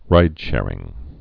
(rīdshârĭng)